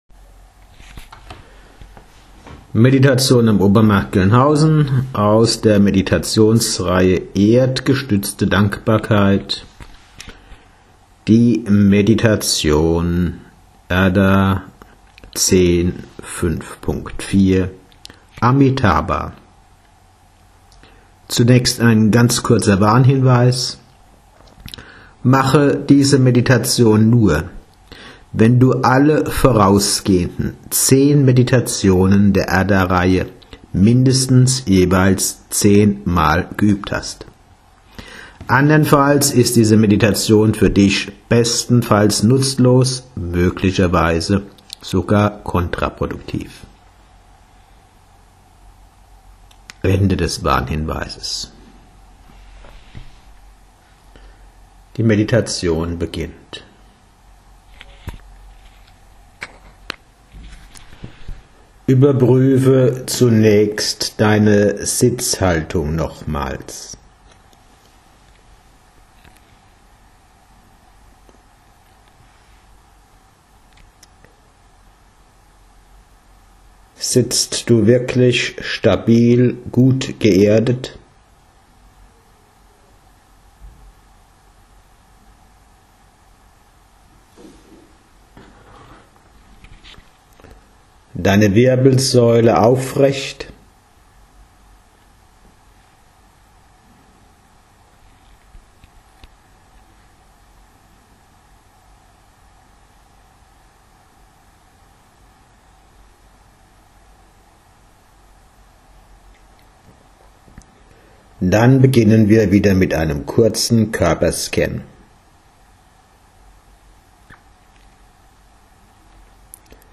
Meditation: Schmetterlinge tr�umen (Audio-Datei, 20 Min.)